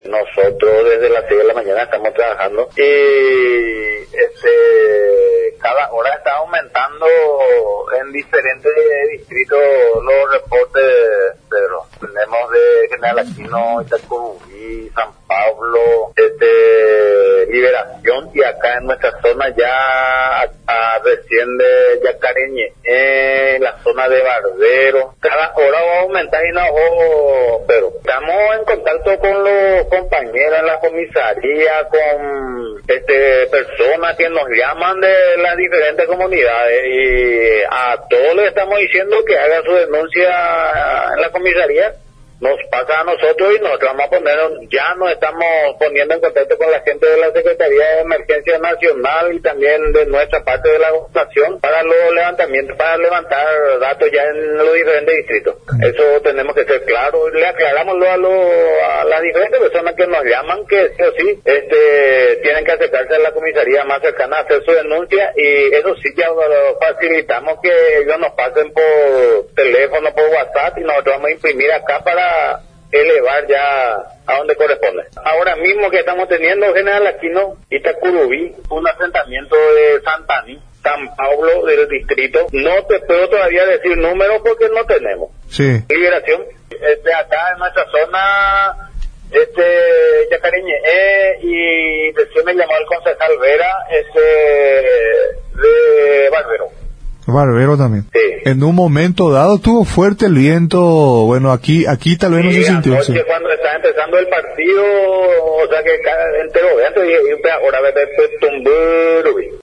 NOTA: PROF. WILFRIDO SAIZ-SECRETARIO DE GESTIÓN DE REDUCCIÓN Y RIESGOS DE LA GOBERNACIÓN.